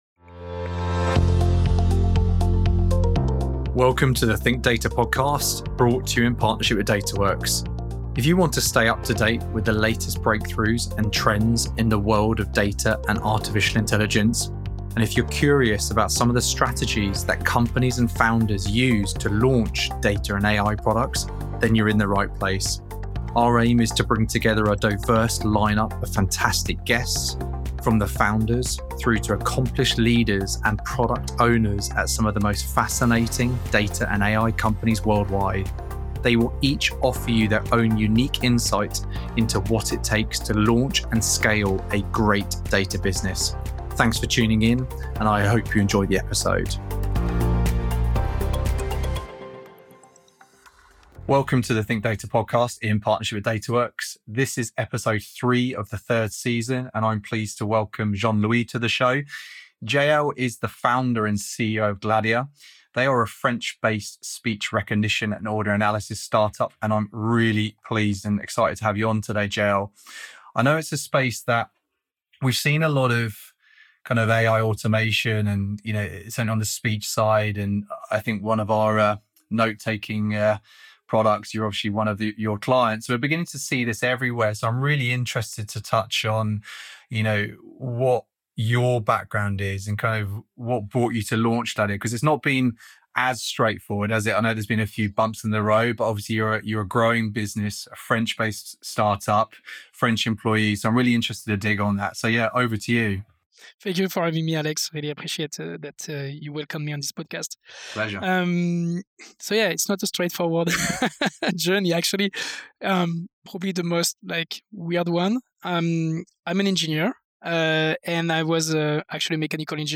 This episode offers one of the most open, honest, and upfront conversations yet, providing real insight into what it takes to launch and scale an early-stage startup.